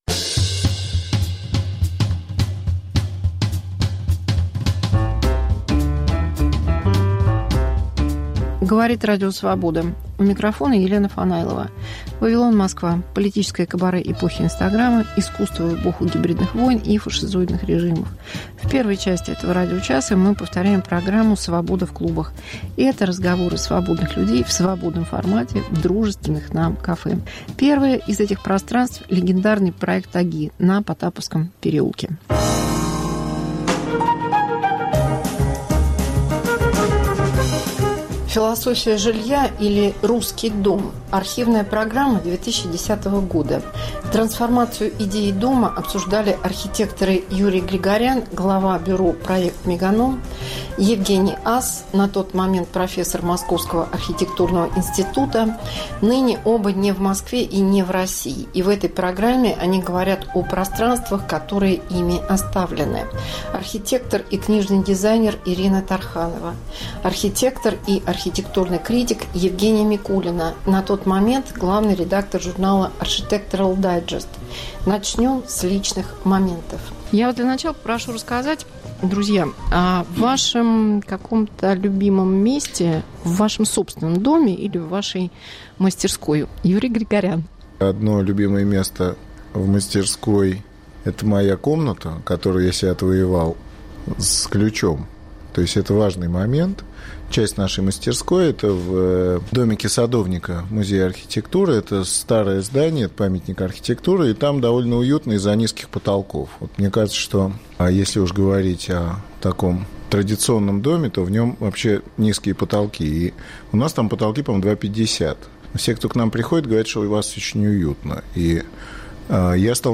Мегаполис Москва как Радио Вавилон: современный звук, неожиданные сюжеты, разные голоса